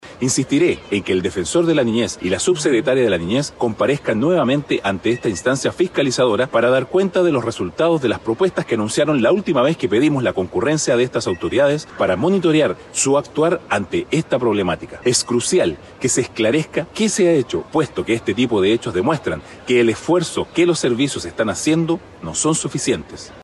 Además, tras los hechos registrados, el diputado Hotuiti Teao, solicitó la concurrencia del Defensor de la Niñez, Anuar Quesille, y de la Subsecretaria de la Niñez, Verónica Silva, a la próxima sesión de la Comisión de Familia de la Cámara Baja.